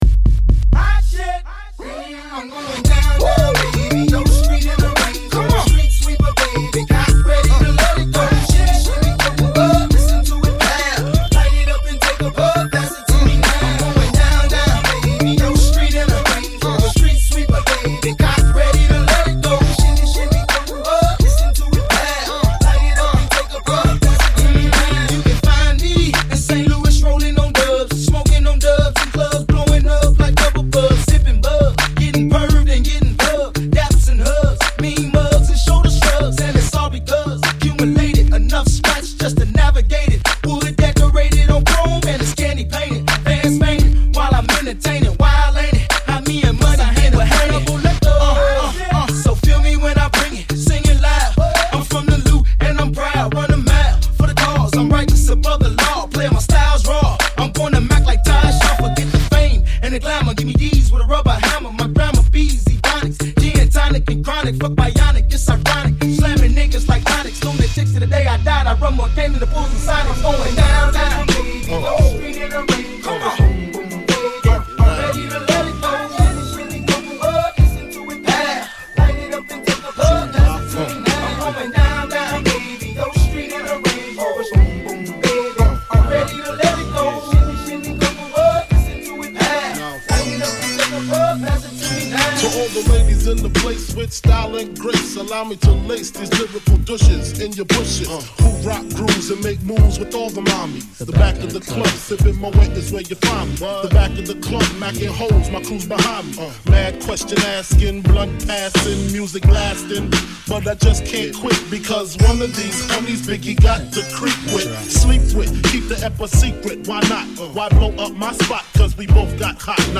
Download his backyard BBQ mix now.